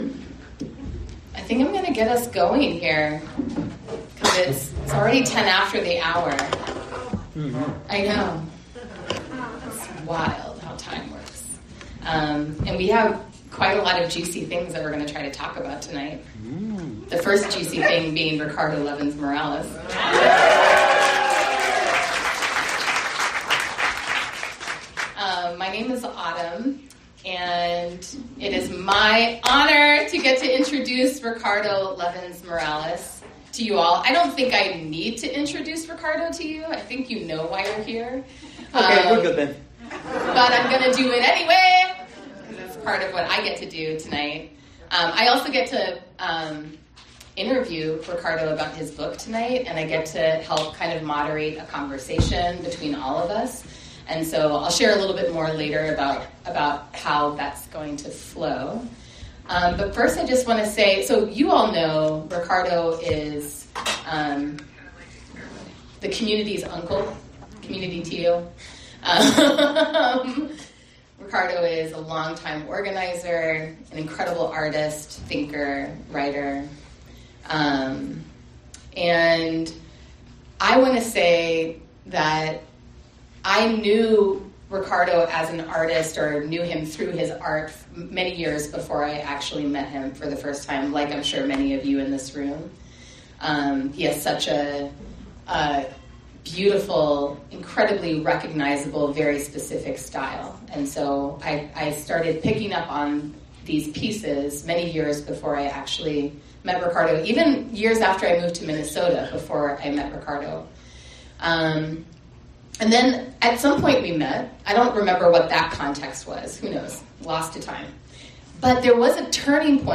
RLM-Book-Launch-Moon-Palace.mp3